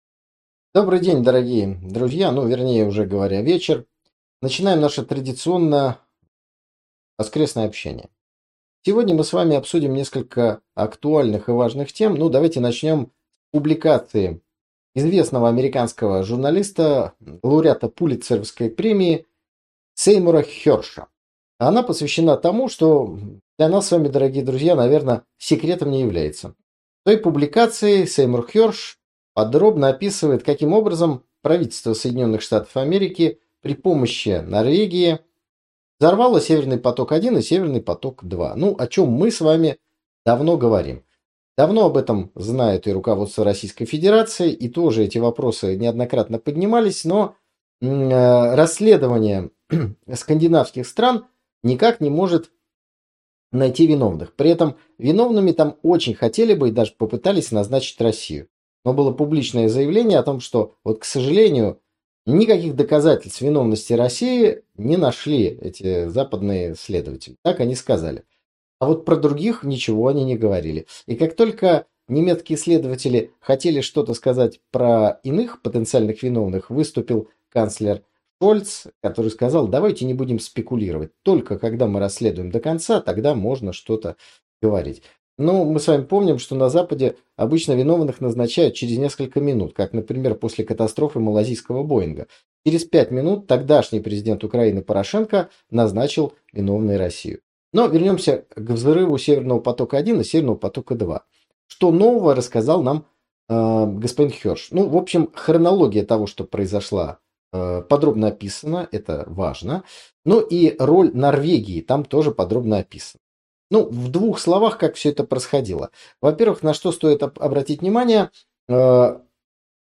В очередном прямом эфире выходного дня поговорили о разоблачении Байдена, подрыве «Северных Потоков» и самолётах НАТО на Украине.